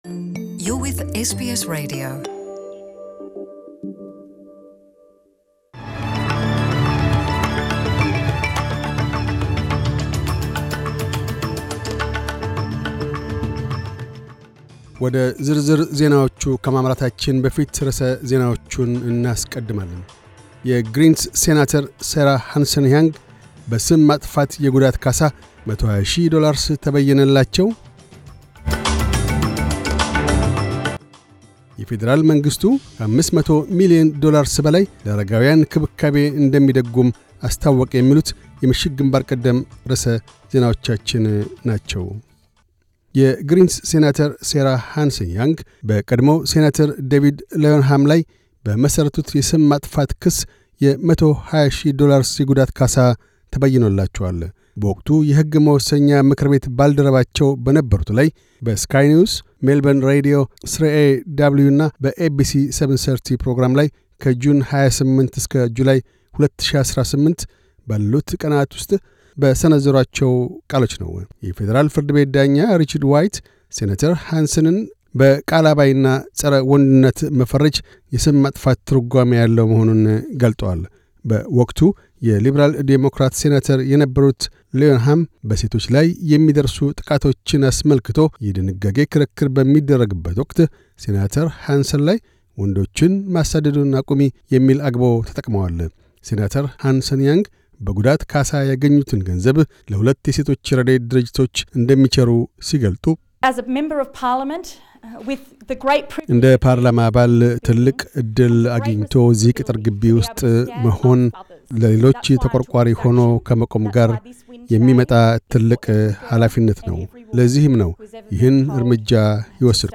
News Bulletin 2511